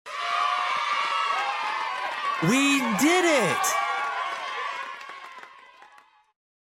Storytoys kids cheering sound effects free download